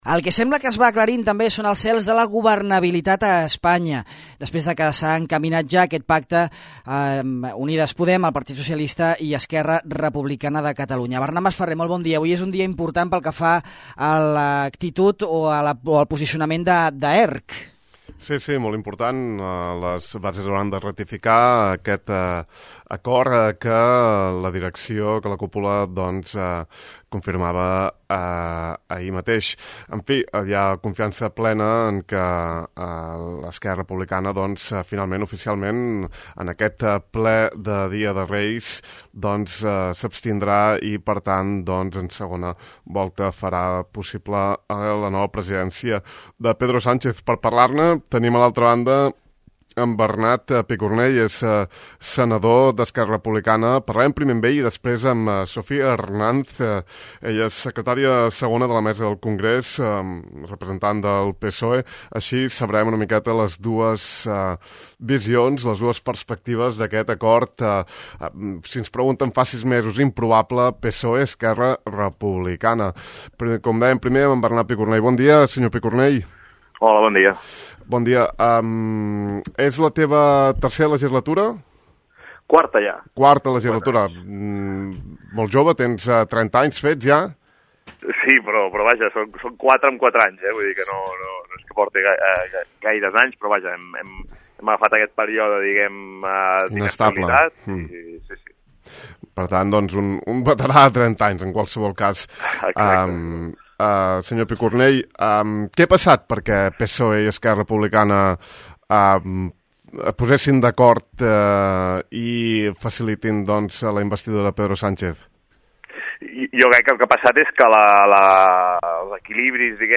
Pocs dies abans de la probable investidura de Pedro Sánchez com a president del Govern estatal, parlam amb el senador d’Esquerra Republicana Bernat Picornell i amb Sofia Hernanz, diputada del PSOE per les Illes Balears sobre acords polítics i futures negociacions.